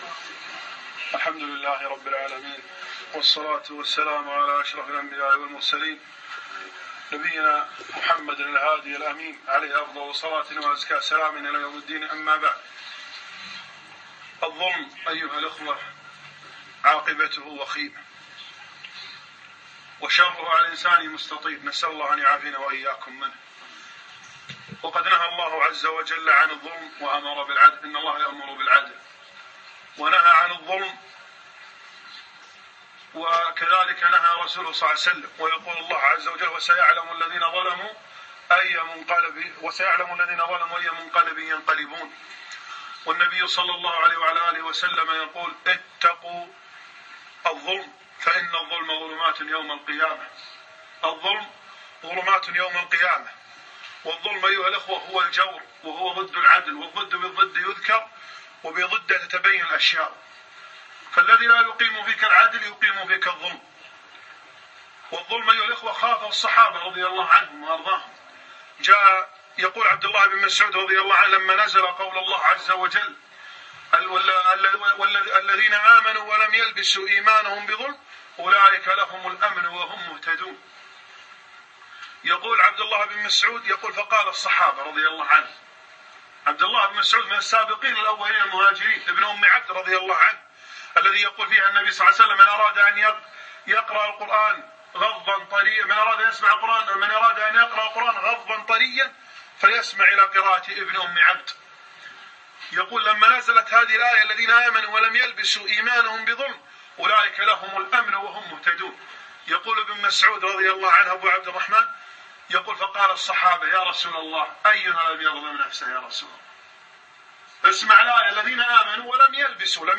الظلم ظلمات...كلمة